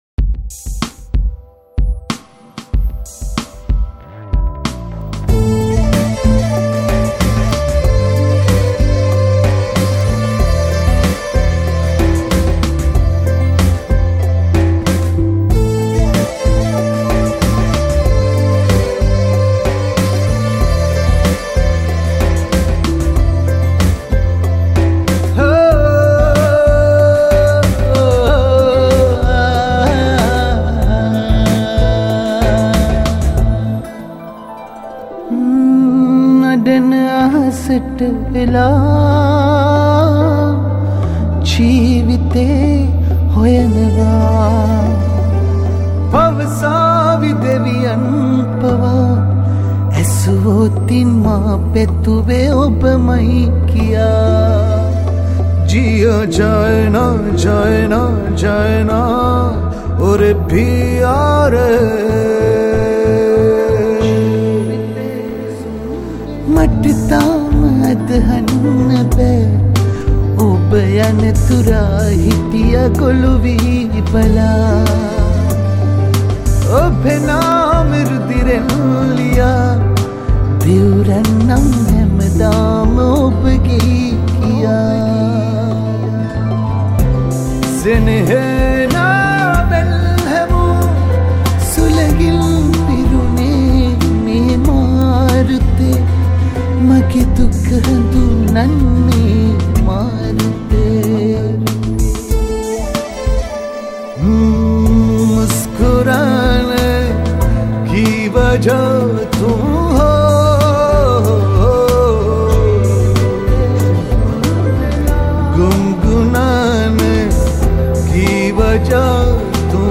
Music Re-Arraged
Guitars